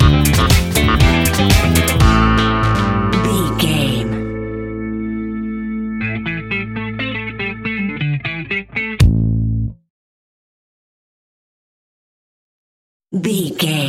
Aeolian/Minor
funky
groovy
uplifting
driving
energetic
bass guitar
electric guitar
drums
synthesiser
electric organ
brass
funky house
disco house
electro funk
upbeat
synth leads
Synth Pads
synth bass
drum machines